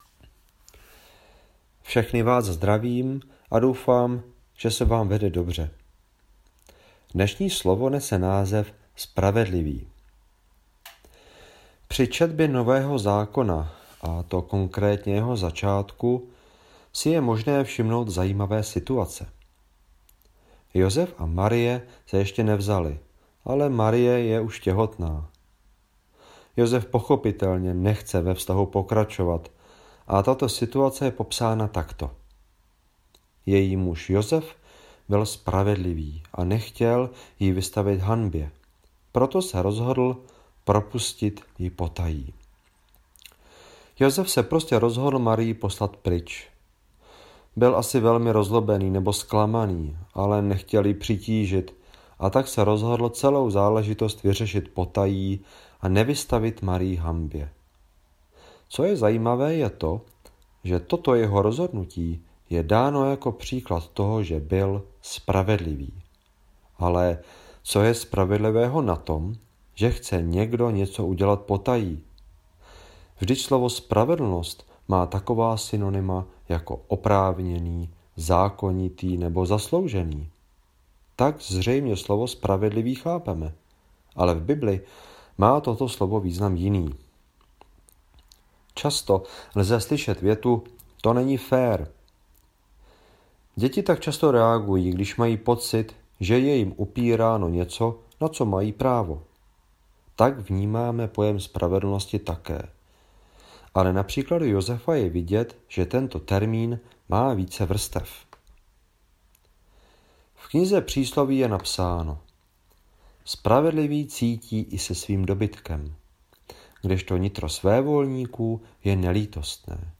Křesťanské společenství Jičín - Kázání 19.4.2020